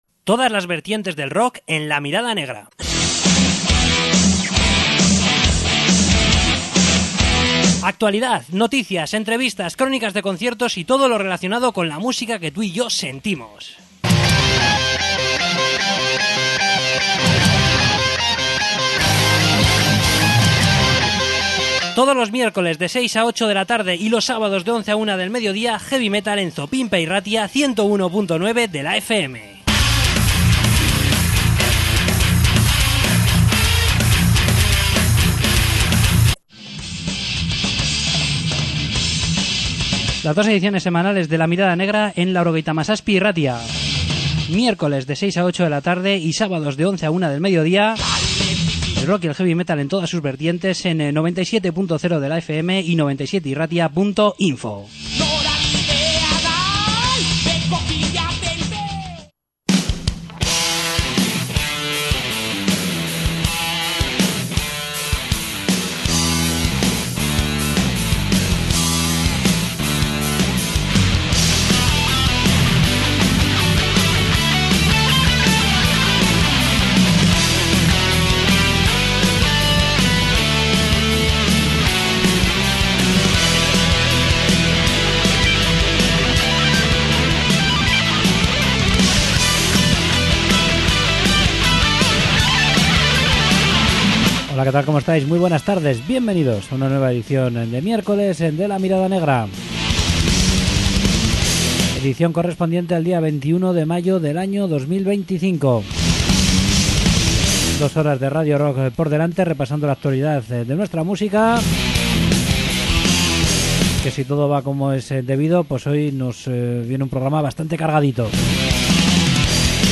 Entrevista con Lotura